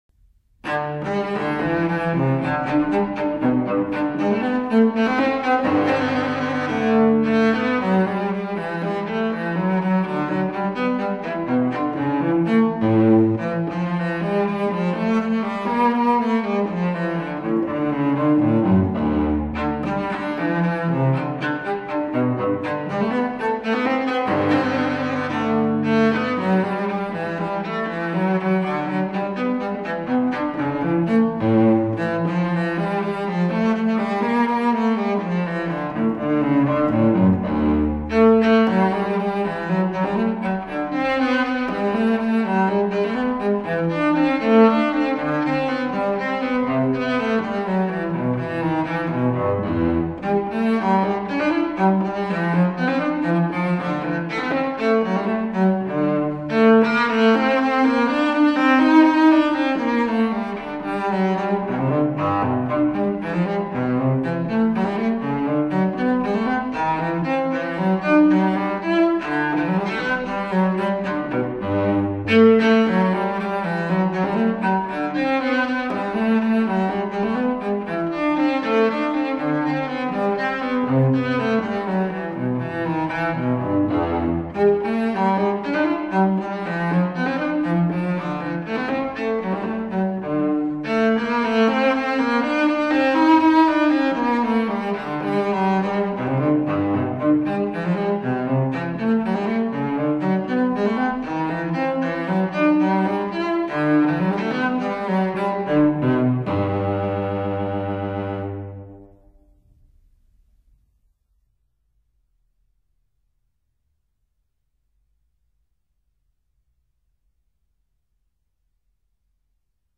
音乐类型：Classical